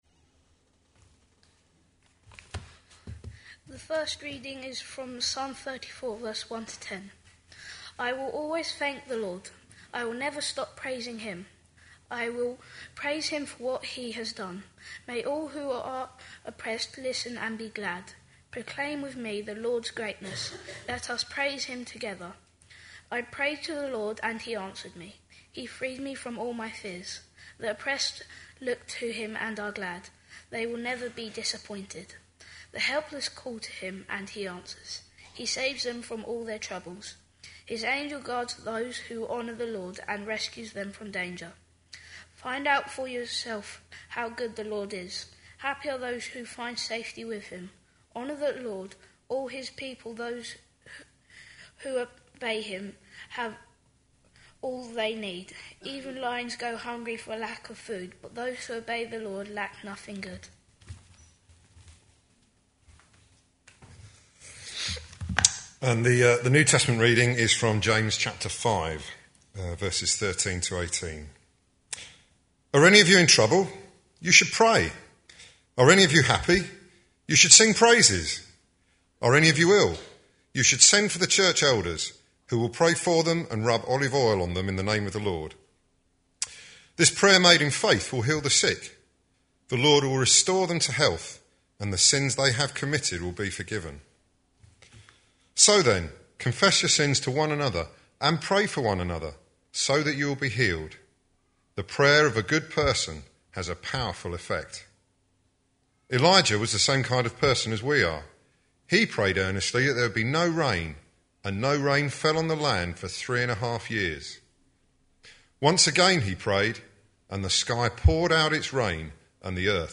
A sermon preached on 4th May, 2014, as part of our Prayer series.